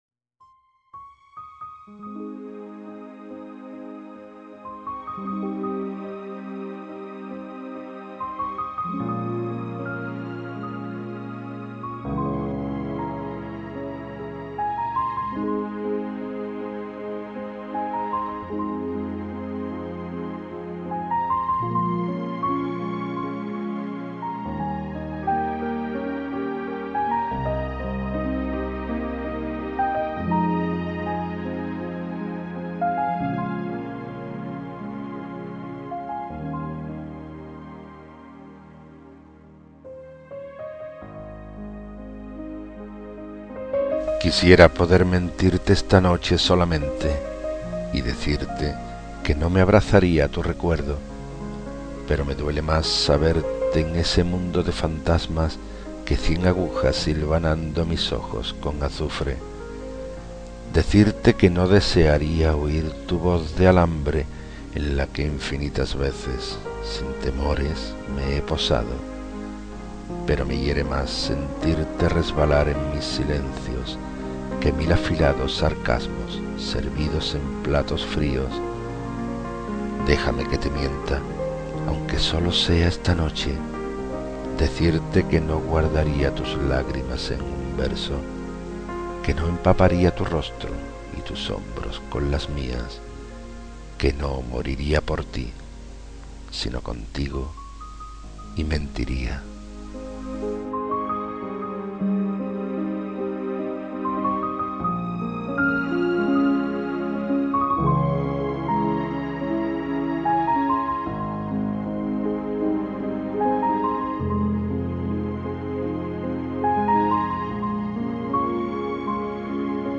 Inicio Multimedia Audiopoemas Mentiría.